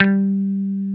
Index of /90_sSampleCDs/Roland L-CD701/GTR_Dan Electro/GTR_Dan-O 6 Str